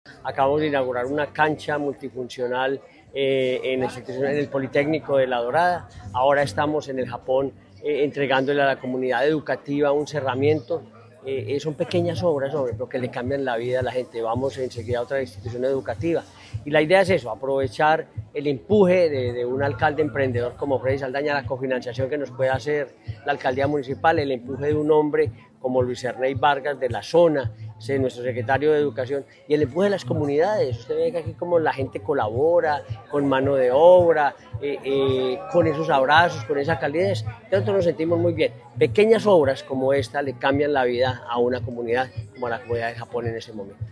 Henry Gutiérrez Ángel, gobernador de Caldas
Gobernador-de-Caldas-Henry-Gutierrez-Angel-obras-educativas-La-Dorada.mp3